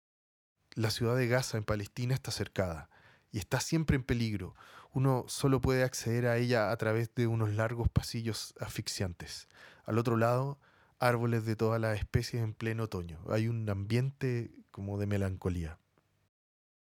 Audioguía La ciudad de Gaza en Palestina está cercada, y está siempre en peligro, uno solo puede acceder a través de unos largos pasillos asfixiantes.